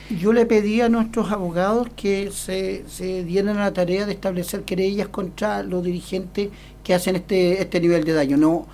Además, el director del Servicio de Salud Concepción, Víctor Valenzuela, confirmó en conversación con Radio Bío Bío la presentación de querellas en contra de los dirigentes que convocaron la paralización por el perjuicio que han causado a los usuarios de ambos recintos asistenciales.